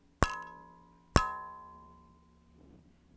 • small hit in the oven thermometer.wav
A short ding specific sound, generated by hitting a small oven thermometer.
small_hit_in_the_oven_thermometer_w0X.wav